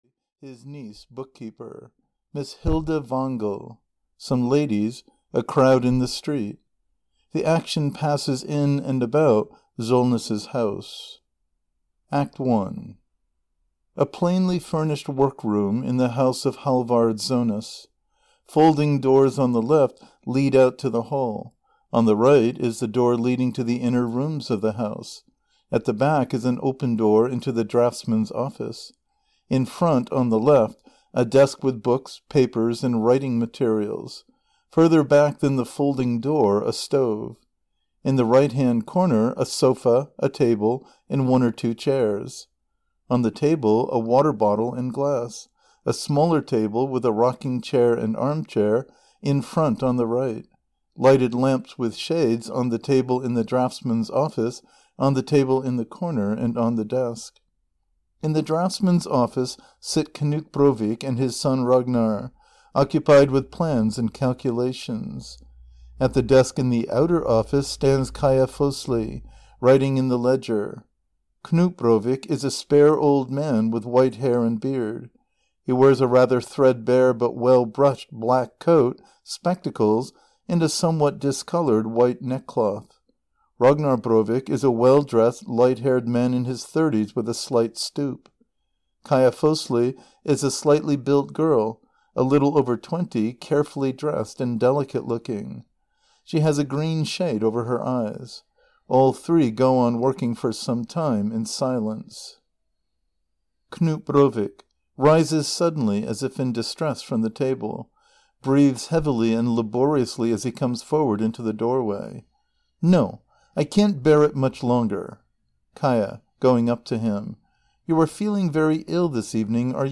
The Master Builder (EN) audiokniha
Ukázka z knihy